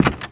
STEPS Wood, Creaky, Walk 22.wav